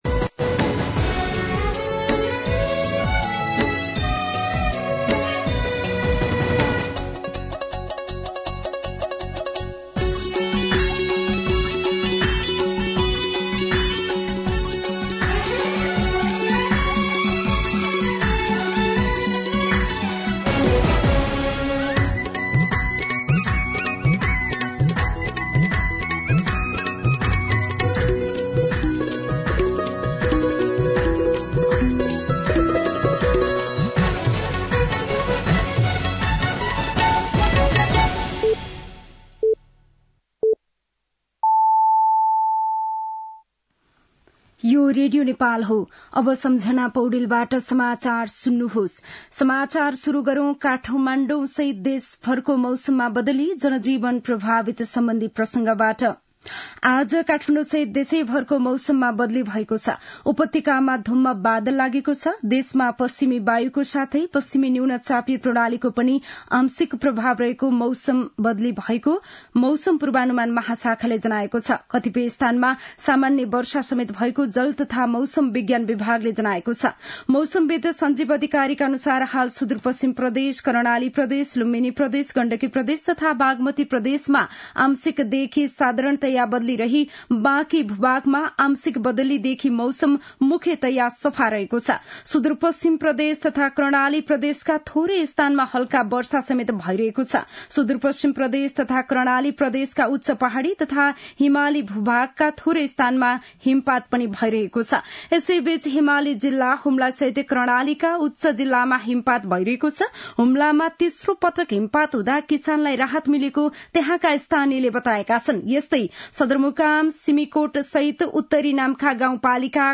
दिउँसो ४ बजेको नेपाली समाचार : १४ पुष , २०८१